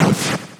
snd_bomb.wav